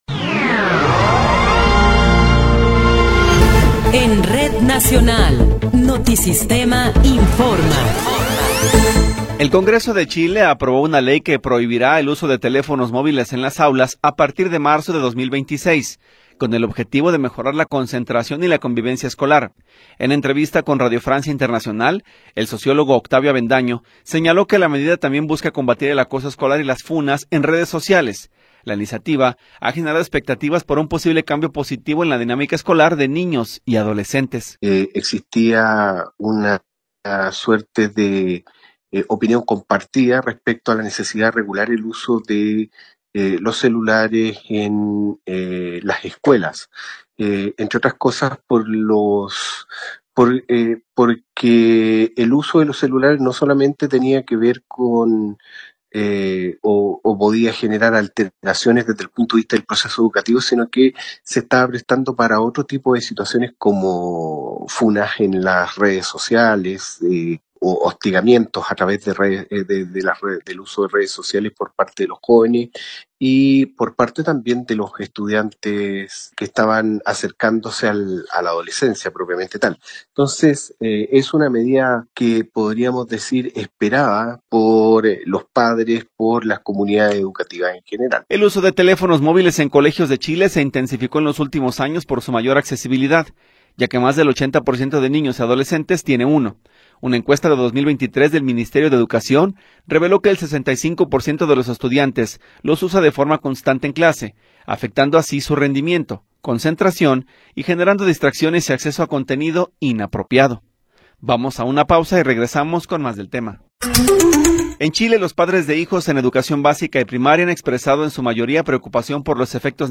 Noticiero 14 hrs. – 1 de Enero de 2026